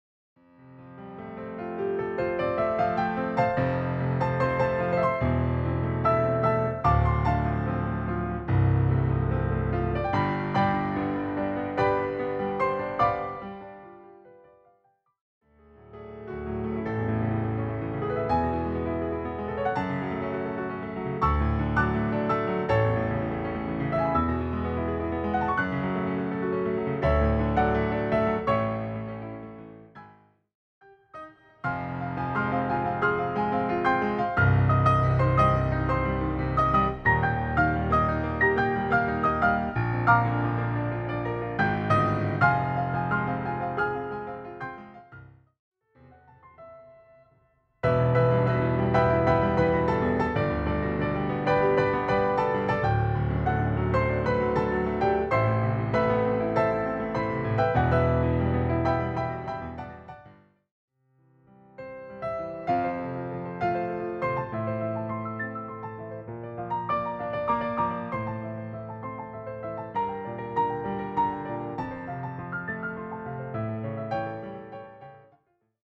a piano collection
translate to a solo piano setting.